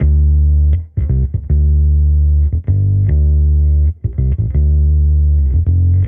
Index of /musicradar/sampled-funk-soul-samples/79bpm/Bass
SSF_PBassProc1_79D.wav